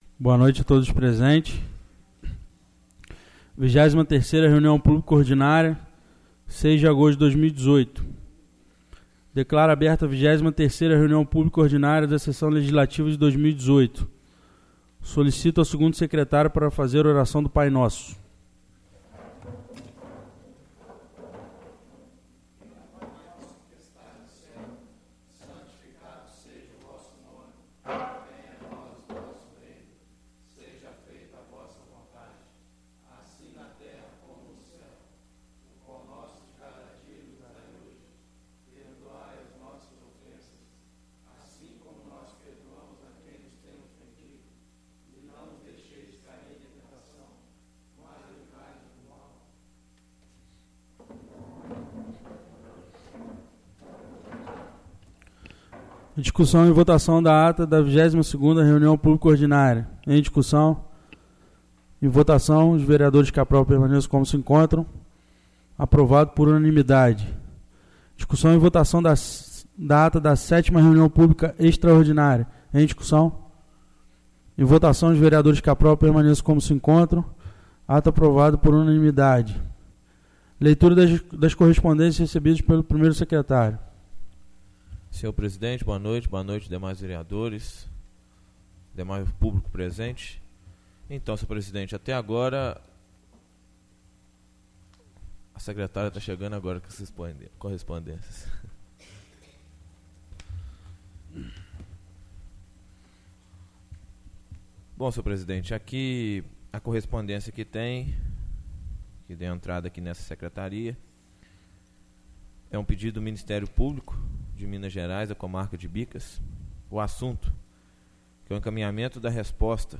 25º Reunião Pública Ordinária 20/08/2016